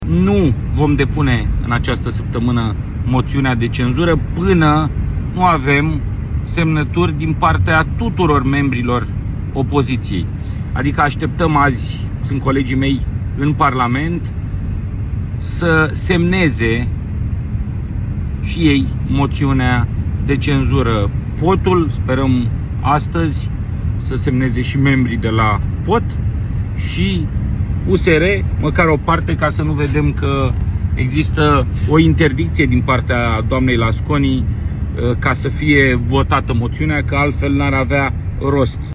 Președintele AUR, George Simion: „Sperăm, astăzi, să semneze și membrii de la POT și USR, măcar o parte”